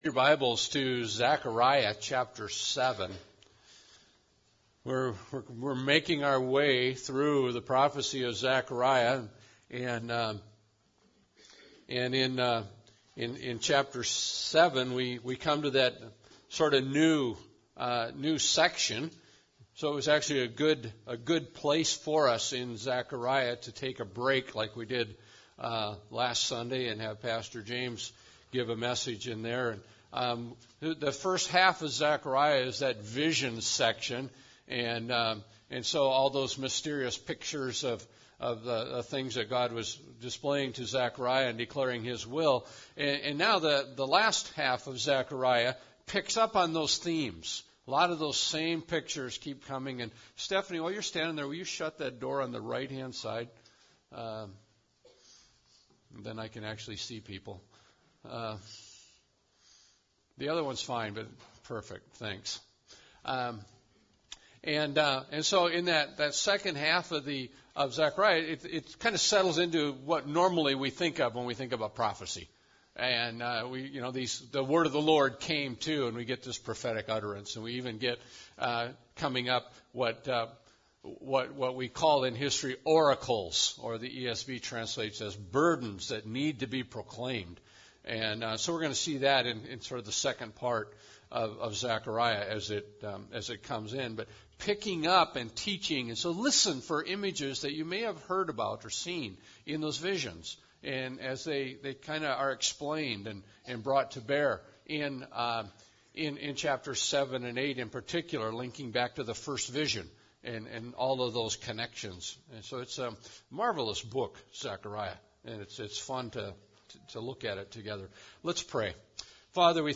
Passage: Zechariah 7 Service Type: Sunday Service